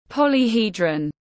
Khối đa diện tiếng anh gọi là polyhedron, phiên âm tiếng anh đọc là /ˌpɒl.iˈhiː.drən/.
Polyhedron /ˌpɒl.iˈhiː.drən/